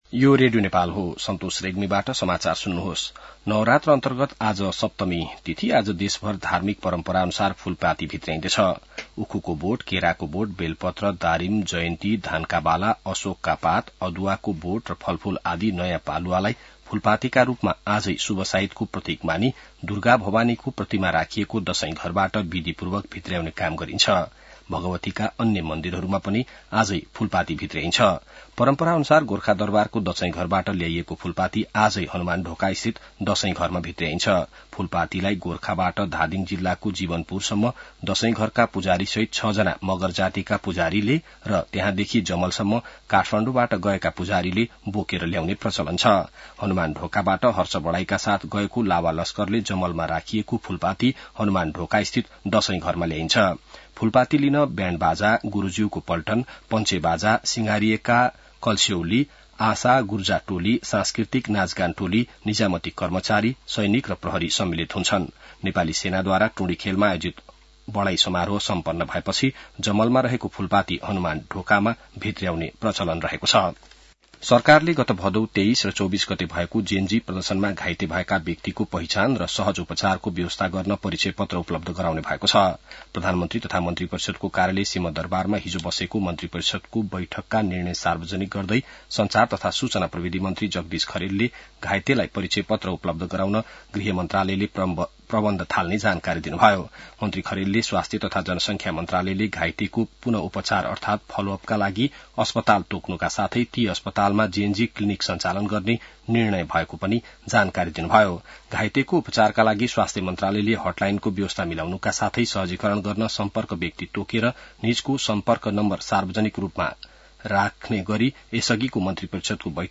बिहान ६ बजेको नेपाली समाचार : १३ असोज , २०८२